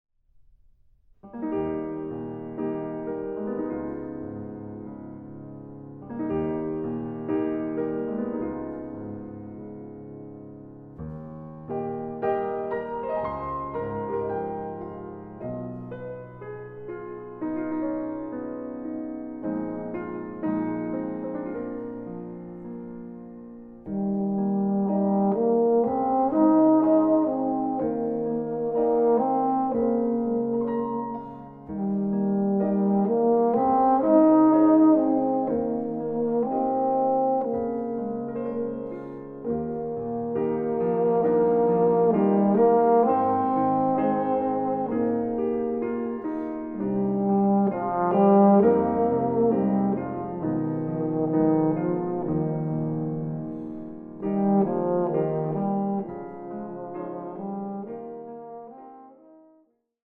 Chinese Traditional
Version for Euphonium and Piano